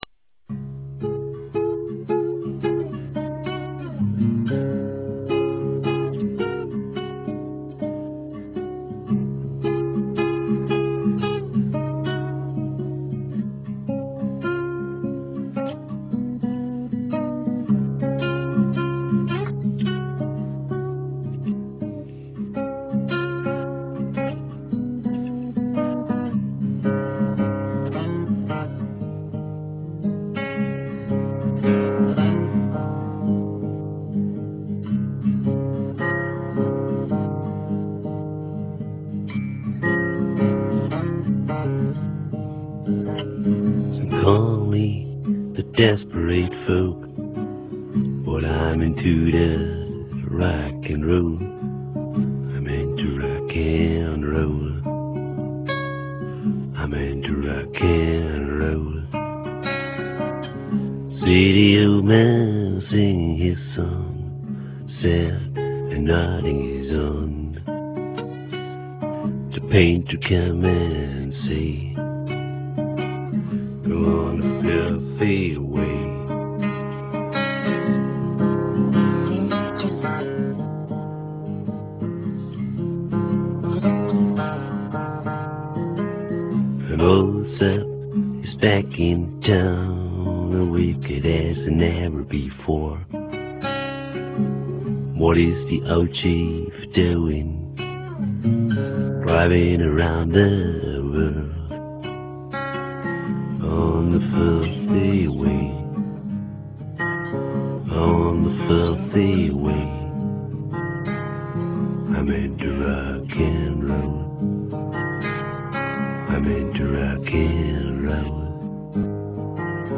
(live and alone)  -320 kb -